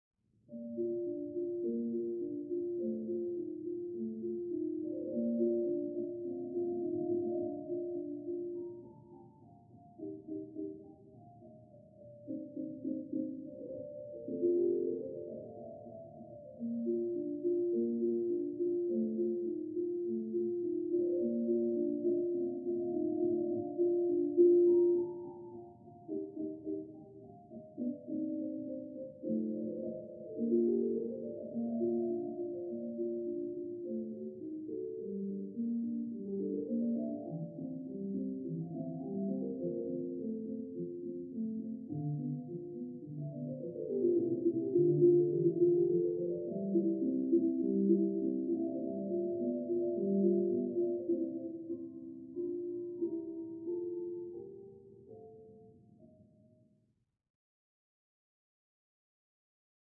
Music; Classical Piano, Through Thick Wall.